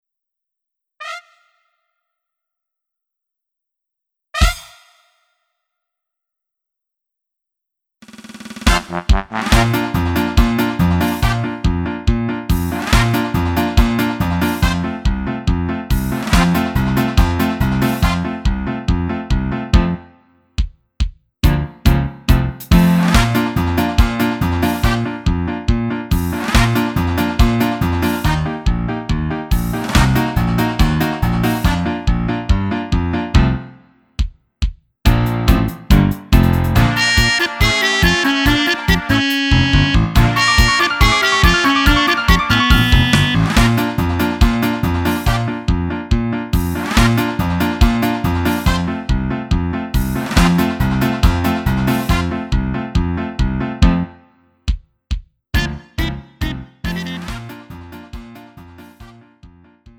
음정 -1키 3:56
장르 구분 Lite MR